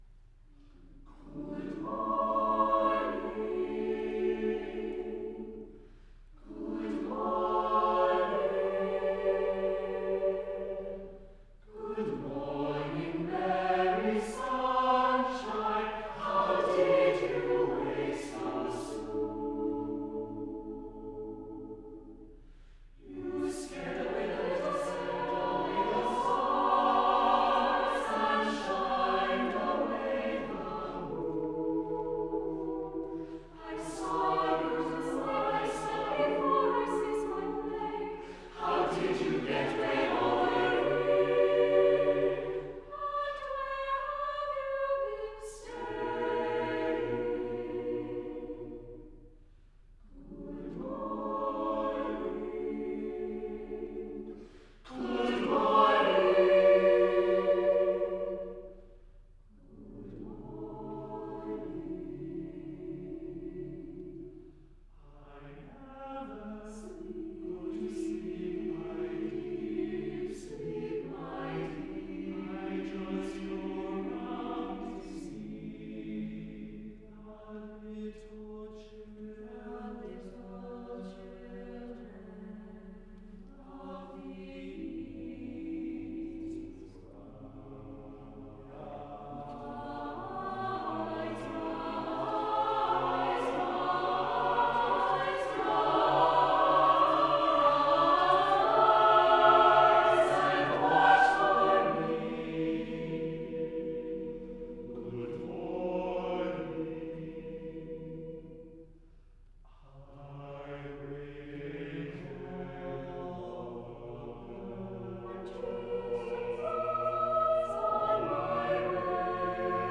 for SATB a cappella choir
The tempo is meant to approximate a relaxed speaking pace.